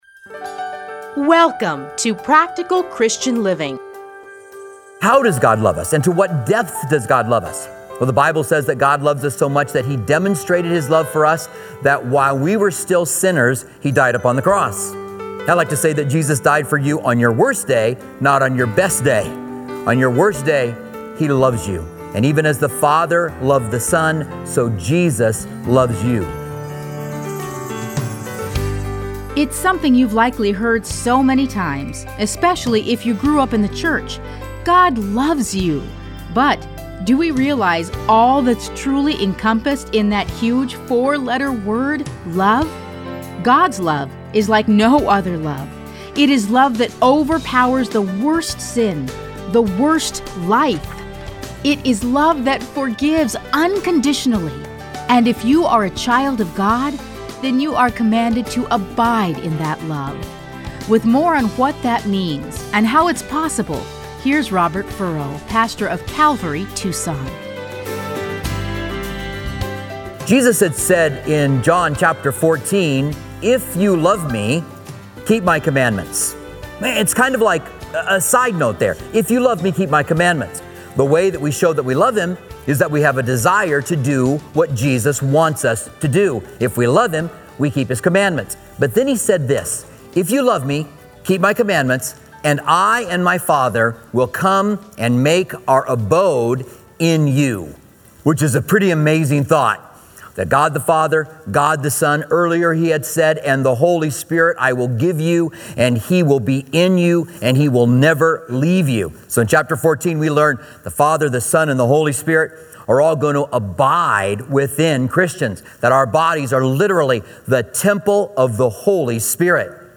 Listen to a teaching from John John 15:8-27.